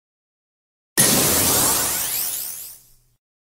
دانلود آهنگ تلپورت 3 از افکت صوتی طبیعت و محیط
جلوه های صوتی
برچسب: دانلود آهنگ های افکت صوتی طبیعت و محیط دانلود آلبوم صدای تلپورت (غیب شدن) از افکت صوتی طبیعت و محیط